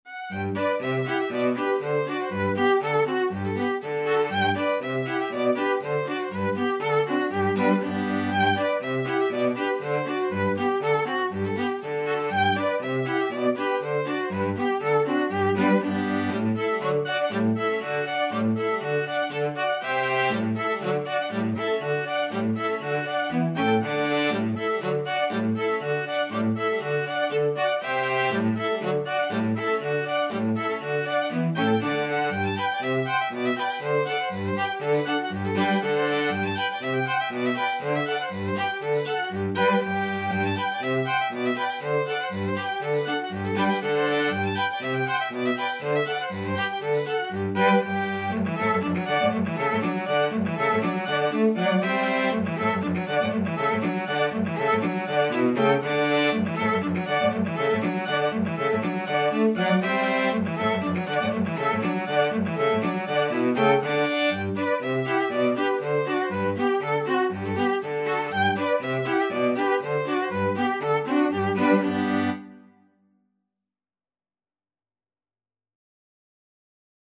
Free Sheet music for String Quartet
Violin 1Violin 2ViolaCello
Allegro =c.120 (View more music marked Allegro)
2/4 (View more 2/4 Music)
G major (Sounding Pitch) (View more G major Music for String Quartet )
Traditional (View more Traditional String Quartet Music)
world (View more world String Quartet Music)